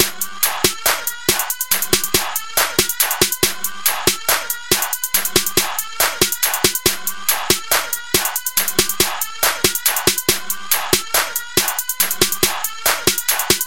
描述：快乐
标签： 140 bpm Hip Hop Loops Drum Loops 2.30 MB wav Key : Unknown
声道立体声